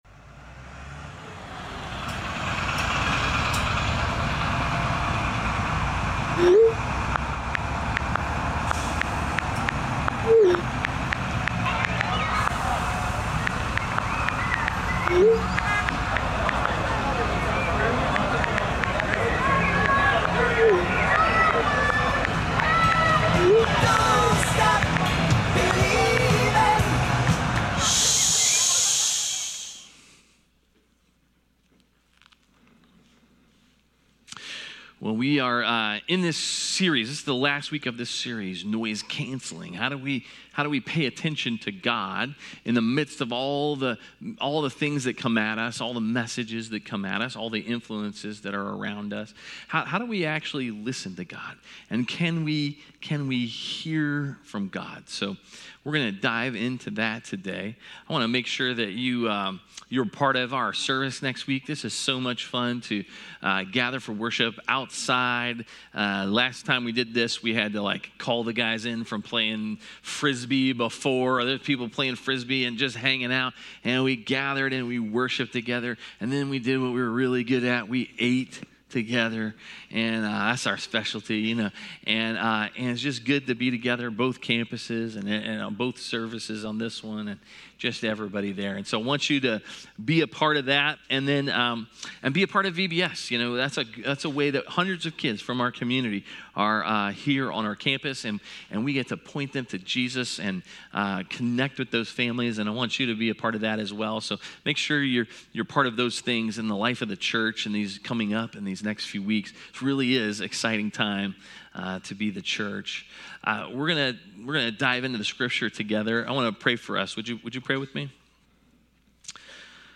A message from the series "Noise Cancelling." Do people still hear from God?